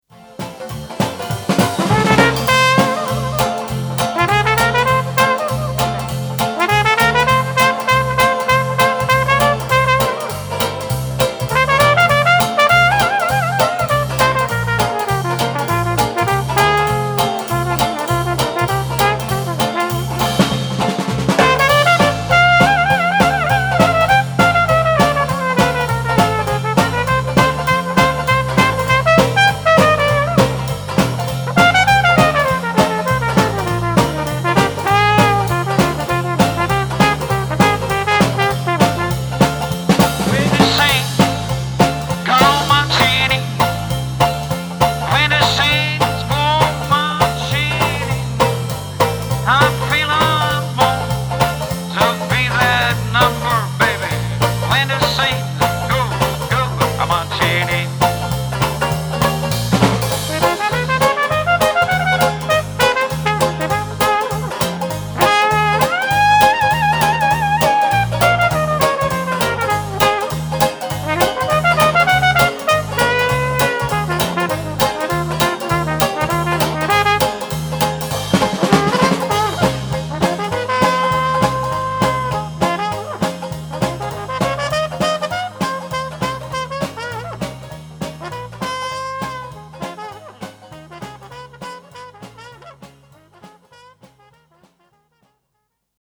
Om u een indruk te geven van het niveau en de kwaliteit van het orkest hebben de Swing Masters een tweetal demo's gemaakt van "Ice Cream" en "When The Saints", twee zeer bekende en alom gewaardeerde nummers uit het dixieland repertoire.